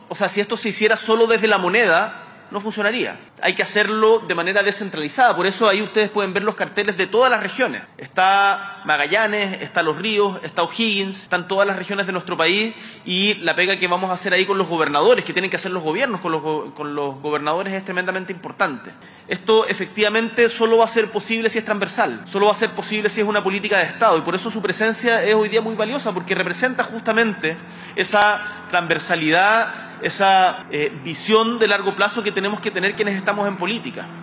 En la actividad, realizada junto a ministros de Estado, autoridades policiales y regionales, se destacó que el plan busca enfrentar los desafíos de conectividad, habitabilidad, seguridad hídrica y energética, además de garantizar condiciones dignas de vivienda, transporte, salud, educación, cultura y seguridad en todo el país.
Por su parte, el Presidente Boric resaltó la importancia de la colaboración público-privada y la transversalidad política para llevar adelante una iniciativa de esta magnitud.